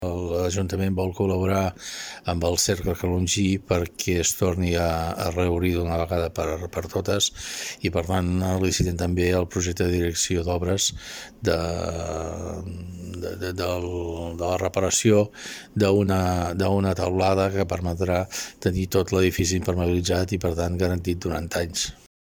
Jordi Soler, alcalde del Calonge i Sant Antoni, explica que l’Ajuntament vol col·laborar perquè el Cercle Calongí “es pugui reobrir d’una vegada per totes”.